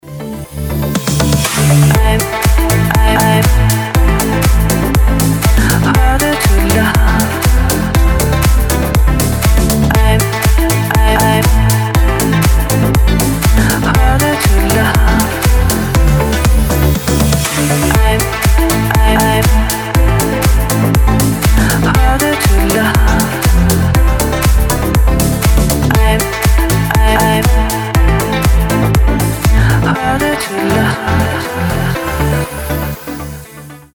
• Качество: 320, Stereo
женский вокал
deep house
Club House